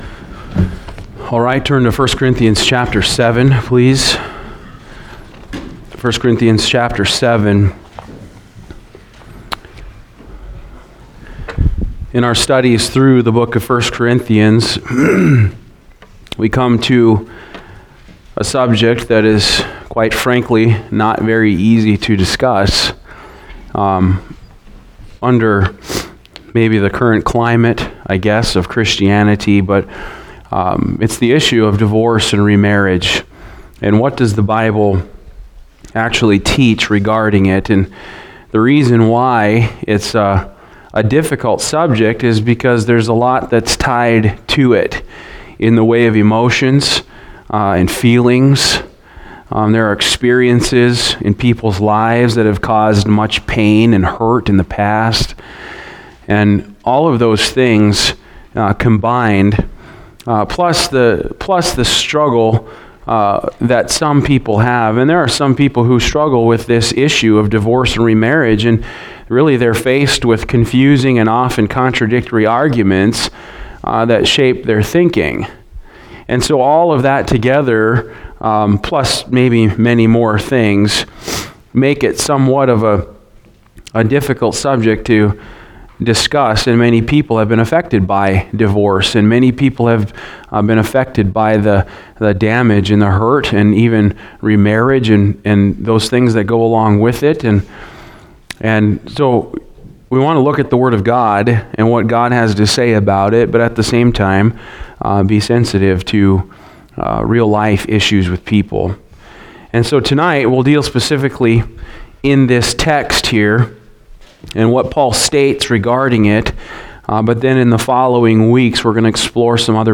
Series: Divorce & remarriage Passage: 1 Corinthians 7:10-16 Service Type: Wednesday Evening